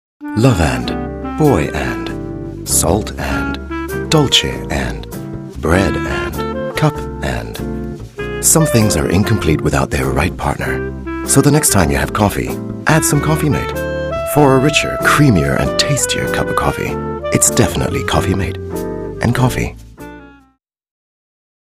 English, low, husky story telling, documentaries,presentations and commercial announcer
Sprechprobe: eLearning (Muttersprache):